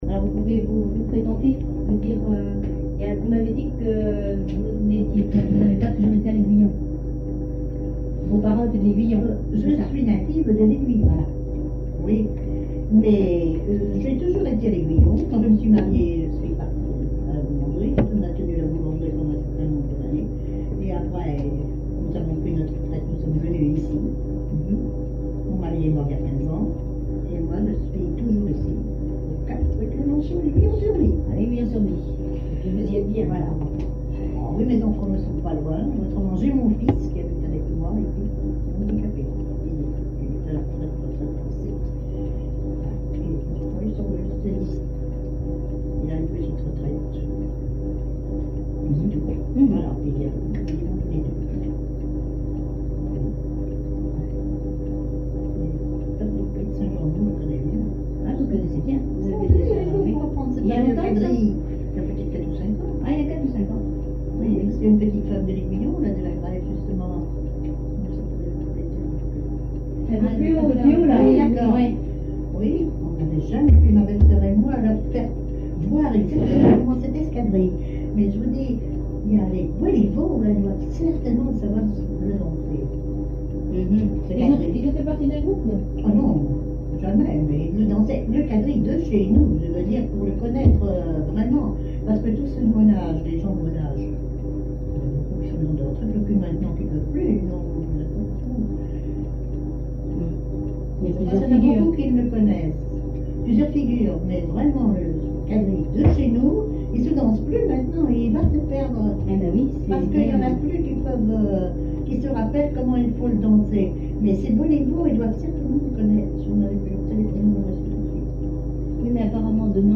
témoignages sur la vie familiale, sur le chant
Catégorie Témoignage